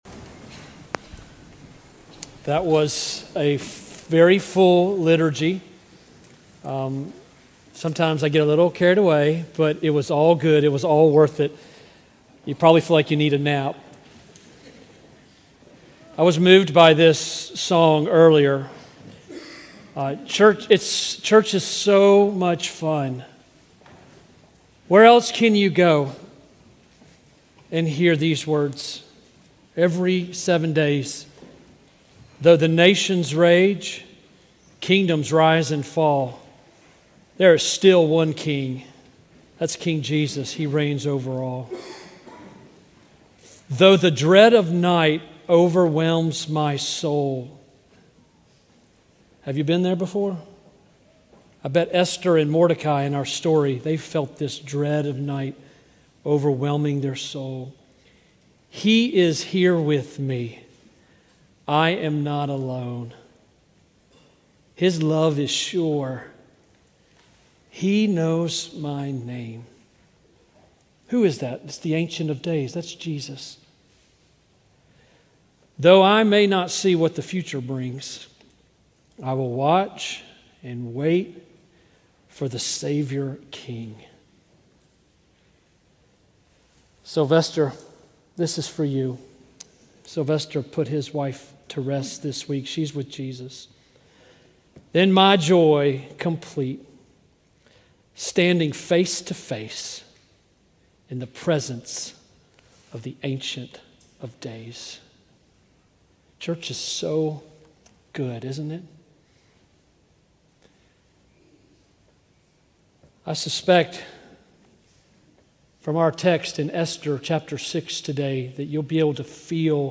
A sermon from the series "Esther." Esther 6:5-7:10 November 2, 2025 Morning